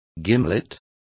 Complete with pronunciation of the translation of gimlet.